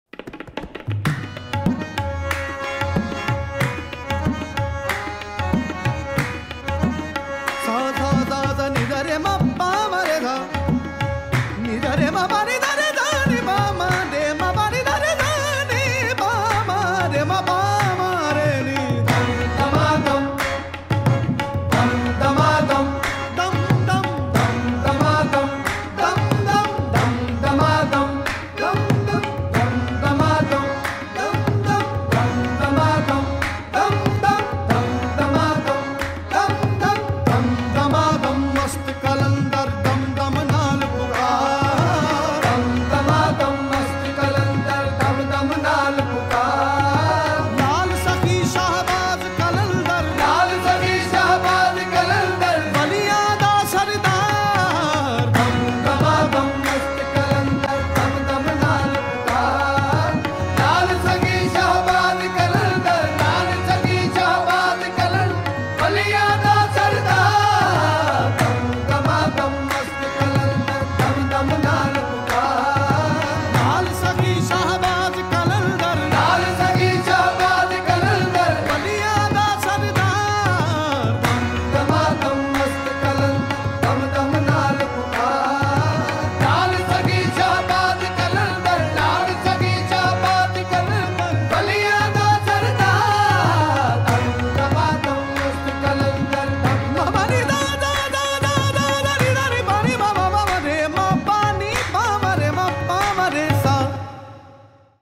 Υπό το φως των κεριών, στο σπίτι του Μαχαραγιά
world-sufi-spirit-festival-jodhpur-rajasthan.mp3